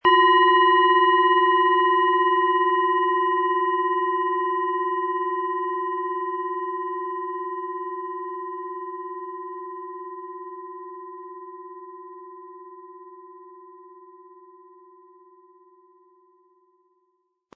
Tibetische Kopf-Schulter- und Bauch-Klangschale, Ø 12,4 cm, 260-320 Gramm, mit Klöppel
Es ist eine von Hand geschmiedete Klangschale, die in alter Tradition in Asien von Hand gefertigt wurde.
Im Sound-Player - Jetzt reinhören hören Sie den Originalton dieser Schale.
Durch die traditionsreiche Herstellung hat die Schale stattdessen diesen einmaligen Ton und das besondere, bewegende Schwingen der traditionellen Handarbeit.